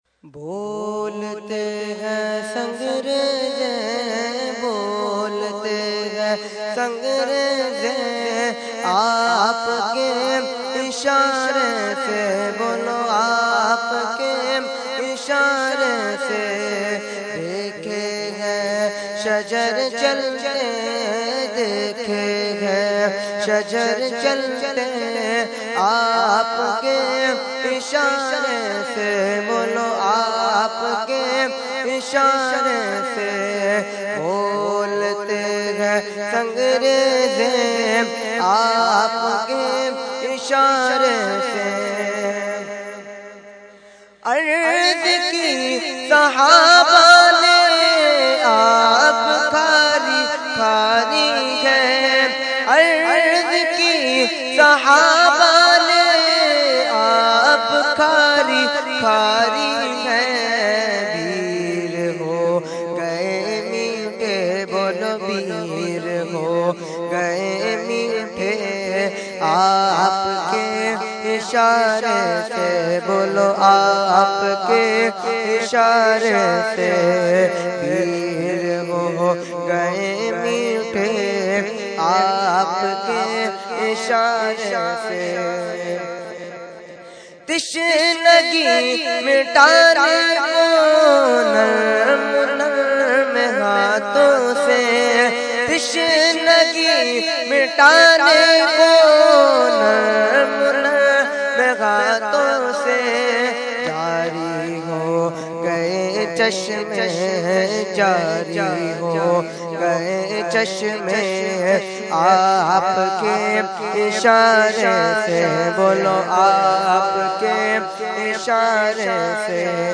Category : Naat | Language : UrduEvent : Urs Qutbe Rabbani 2014